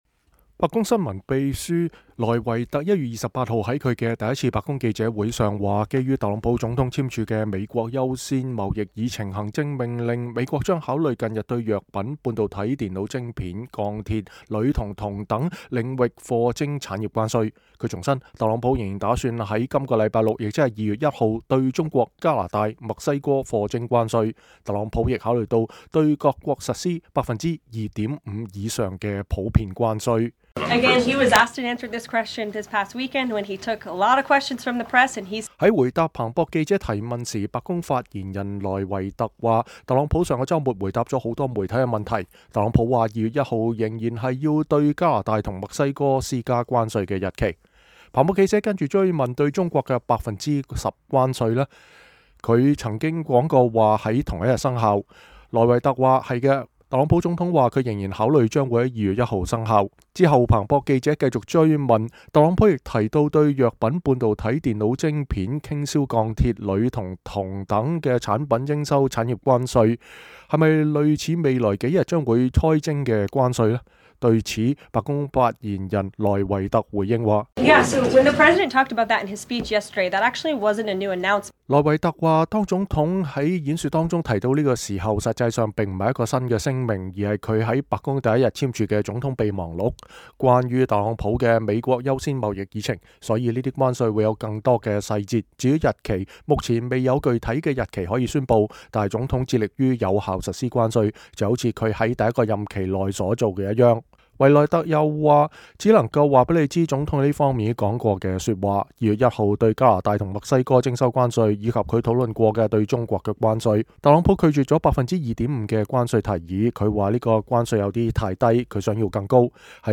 白宮新聞秘書卡洛琳·萊維特(Karoline Leavitt)1月28日在她的第一次白宮記者會上。